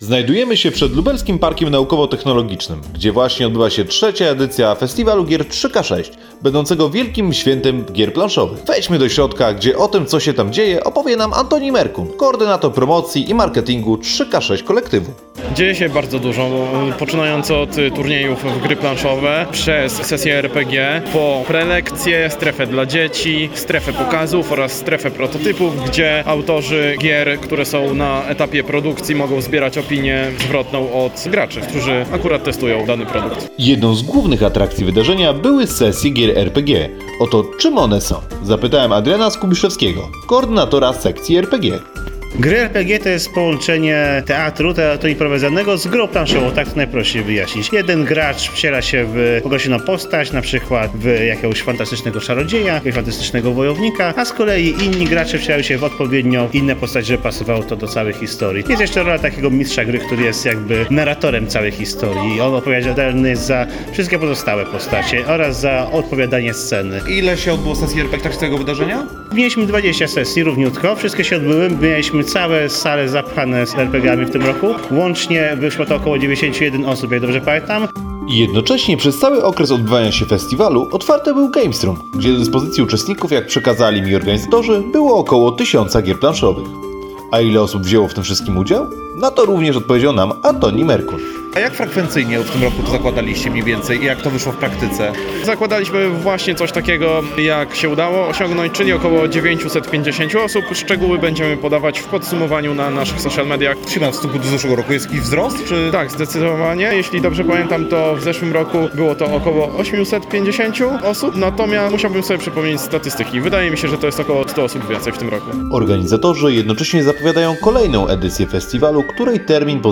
Relacja festiwal 3k6
Relacja-3k6-festiwal-1.mp3